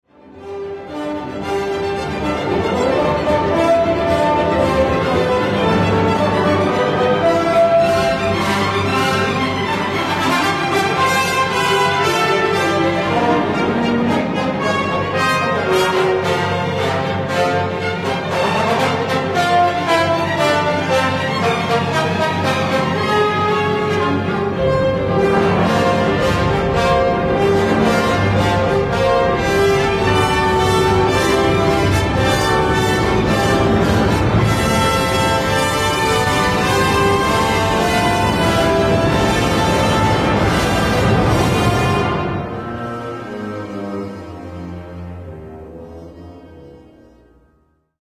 Zakończenie powala energią i rozmachem: